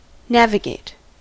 navigate: Wikimedia Commons US English Pronunciations
En-us-navigate.WAV